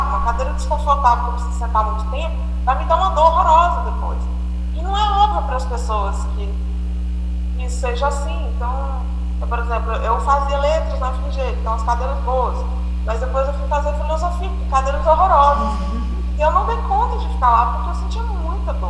Depoimento em áudio